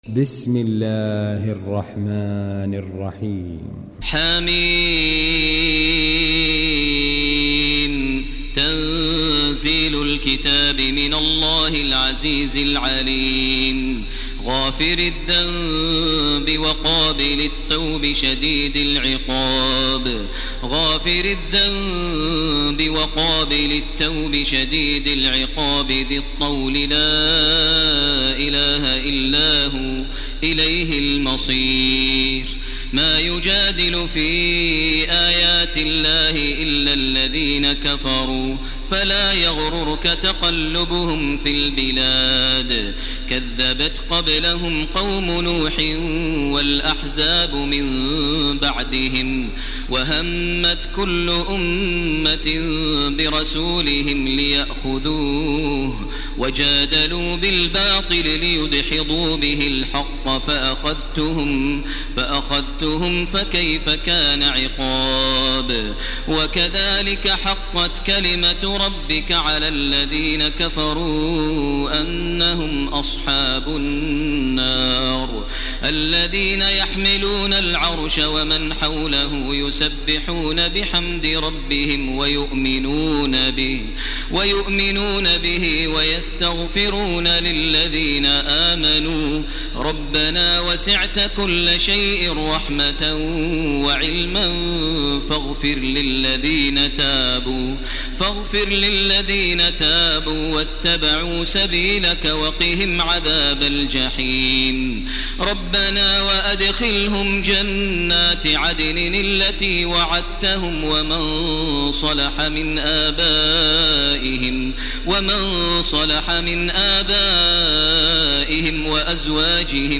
تراويح ليلة 23 رمضان 1430هـ من سور غافر (1-85) و فصلت (1-46) Taraweeh 23 st night Ramadan 1430H from Surah Ghaafir and Fussilat > تراويح الحرم المكي عام 1430 🕋 > التراويح - تلاوات الحرمين